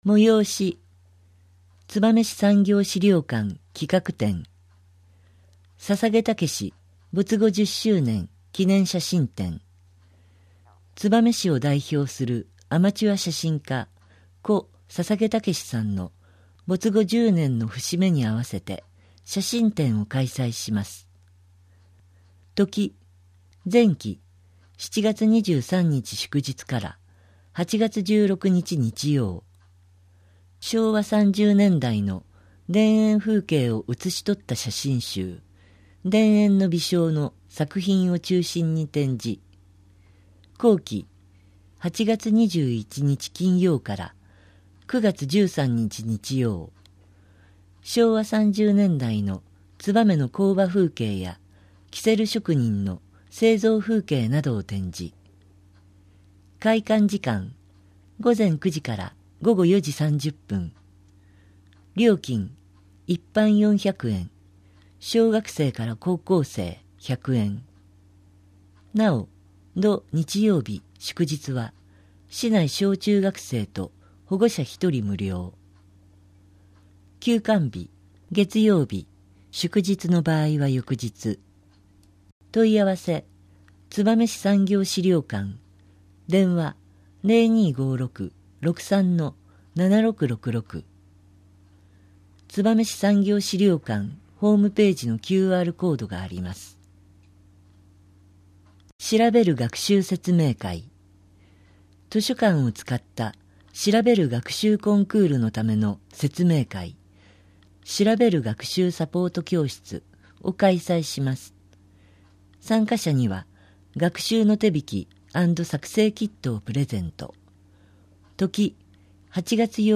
こちらではMP3版の声の広報を、項目ごとに分けて配信しています。